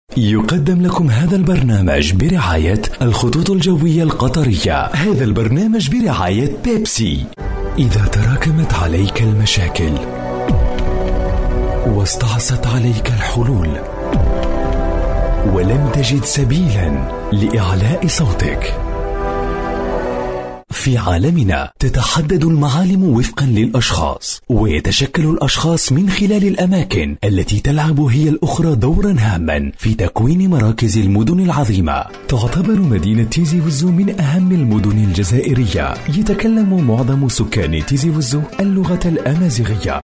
Voix Off Arabe Comédien Chanteur Narrateur Animateur Radio Tv Team Building
Sprechprobe: Industrie (Muttersprache):